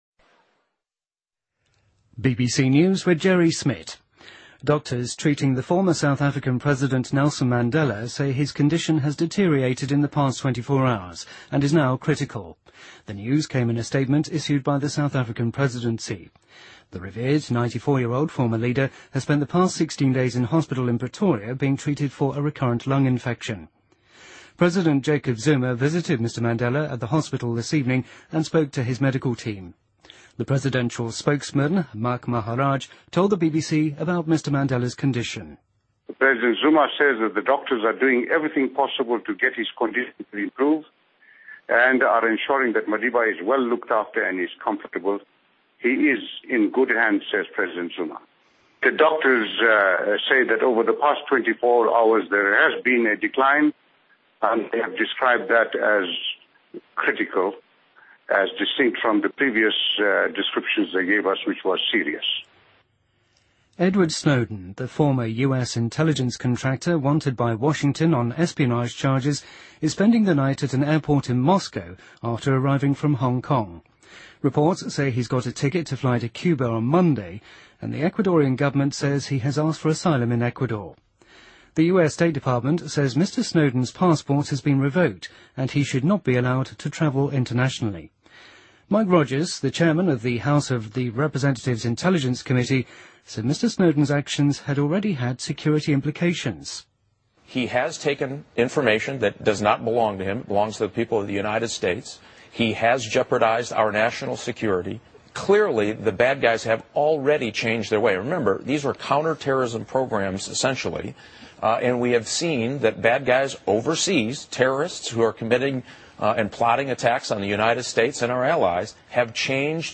BBC news,斯诺登离香港后在莫斯科机场过夜